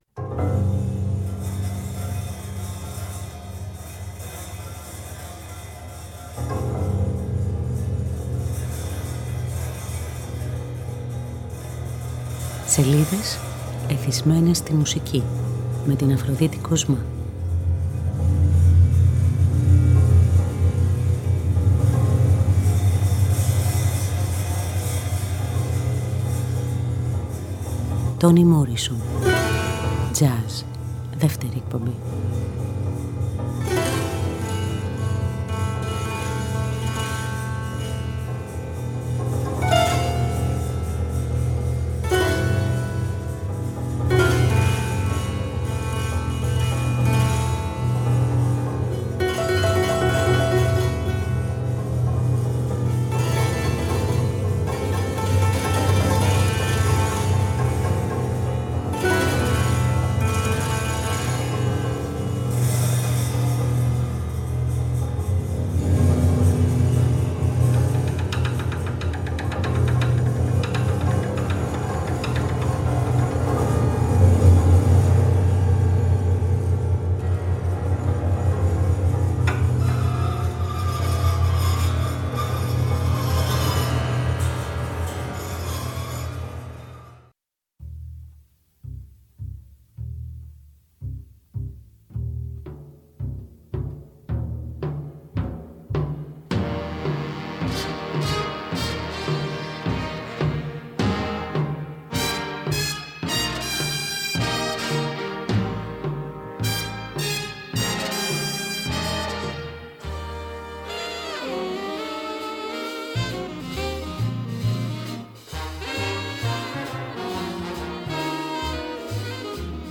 2017 Επιμέλεια, αφήγηση